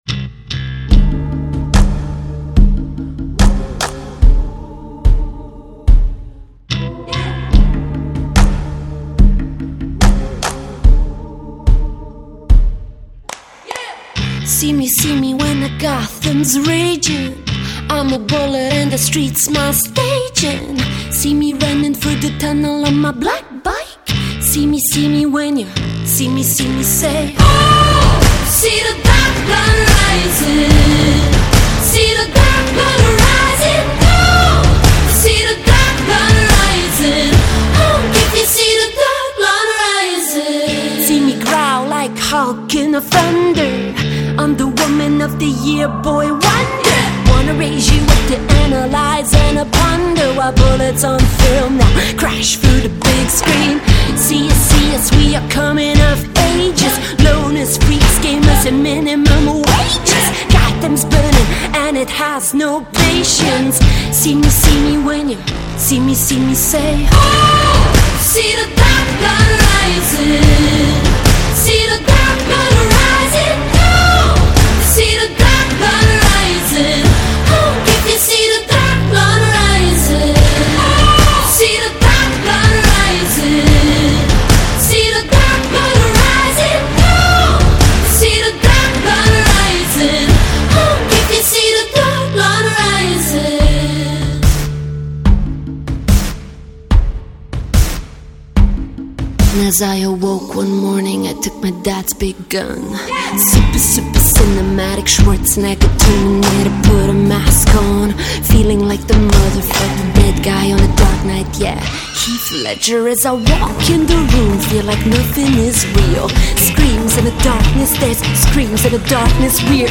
bassplayer, singer/songwriter